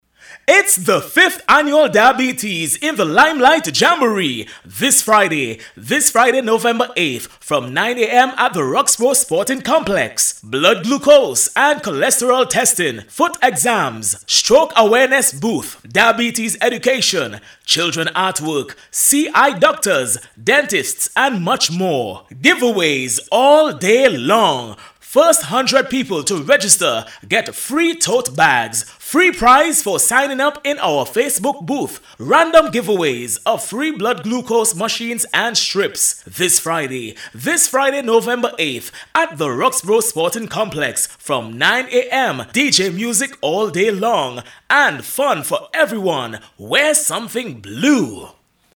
Click through to hear the advertisement for the Jamboree that was played on the radio.